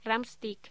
Locución: Rumsteak
Sonidos: Voz humana